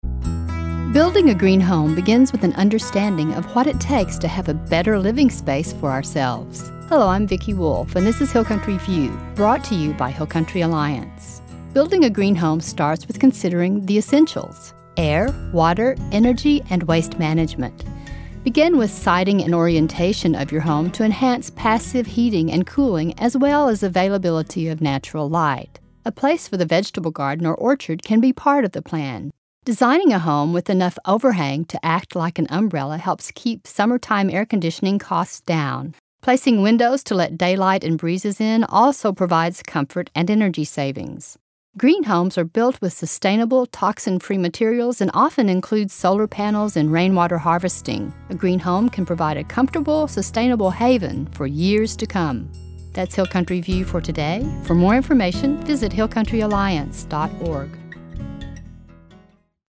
60 Second Radio Spots – 2012